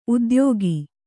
♪ udyōgi